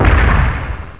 SE_SHAKE.mp3